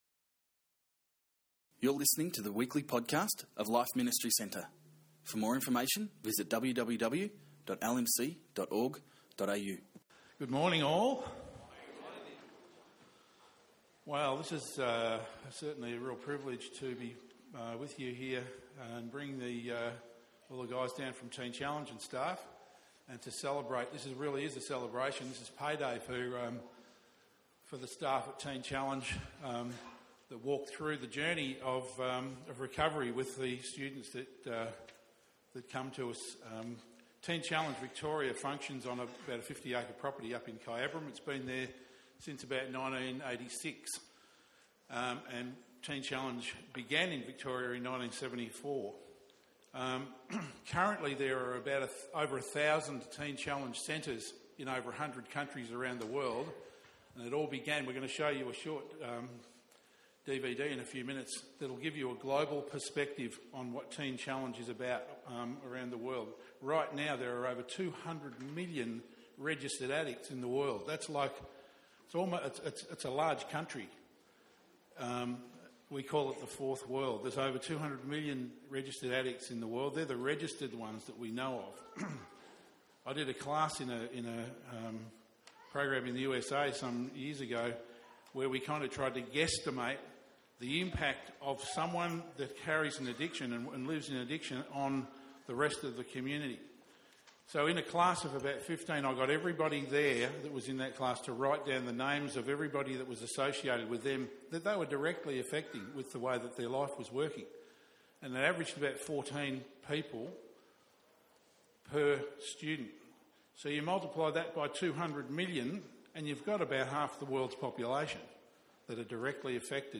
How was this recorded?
Teen Challenge 2013 Celebration Service Teen Challenge shared their 'celebration service' with us. We heard some amazing testimonies of how God turned their life around in the face of addiction.